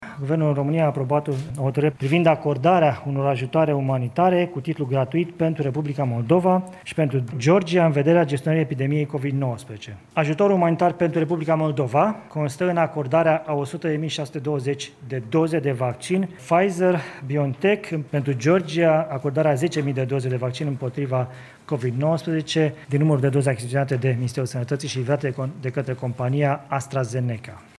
Asigurarea transportului dozelor de vaccin se va realiza de  Departamentul pentru Situații de Urgență sau de Ministerul Apărării Naționale, a spus premierul Florin Cîțu: